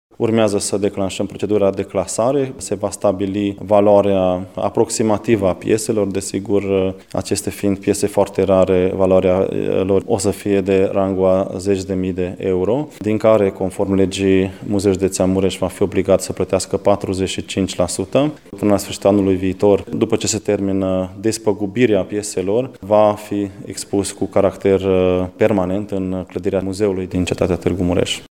Noua descoperire urmează să fie evaluată și va fi expusă publicului peste aproximativ un an, spune directorul Muzeului, Soós Zoltán: